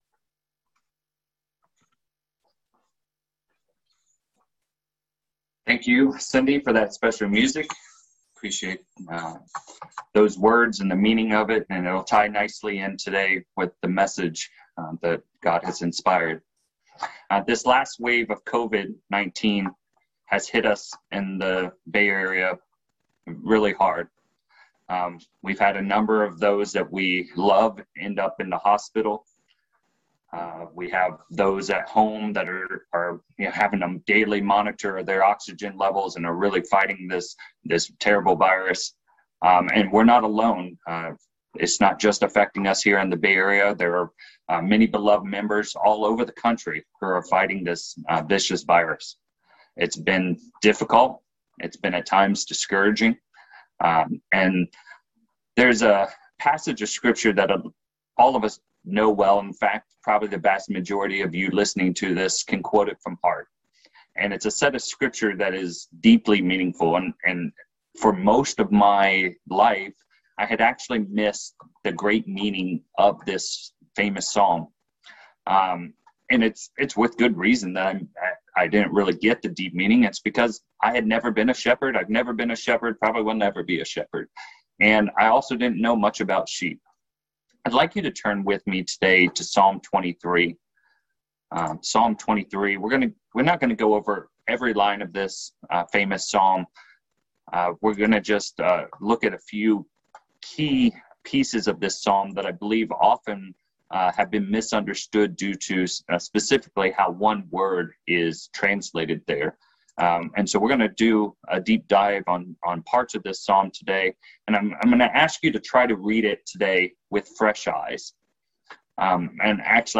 This sermon dives into the often misunderstood lessons of Psalm 23.